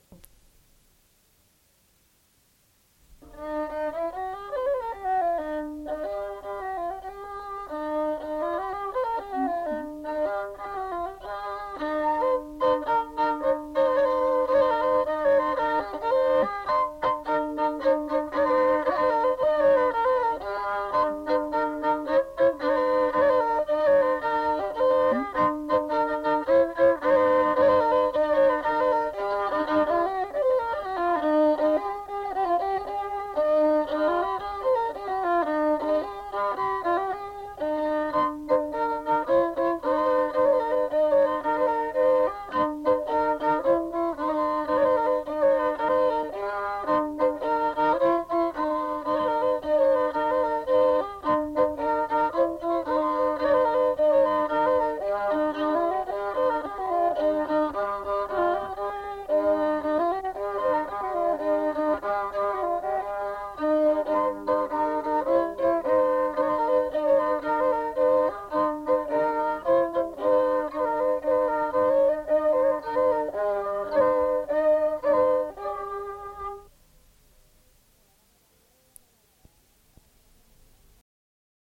Branle